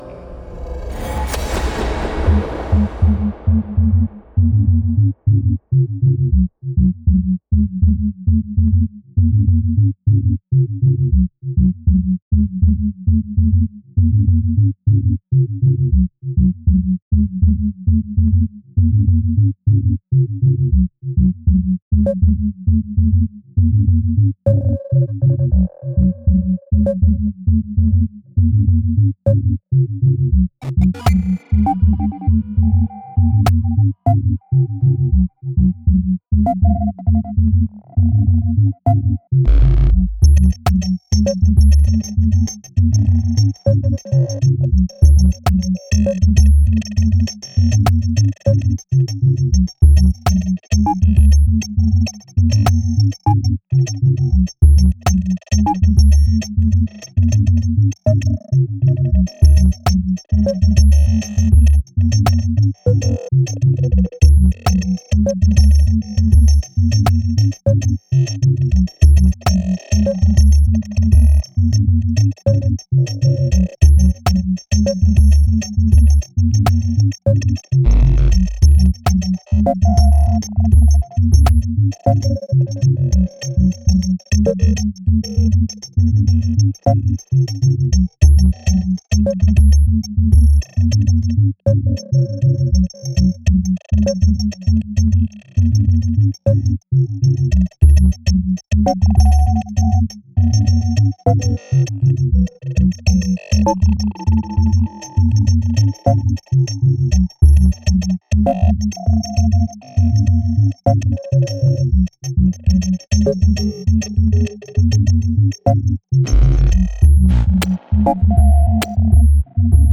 Excellent and extremely elegant electronic music.»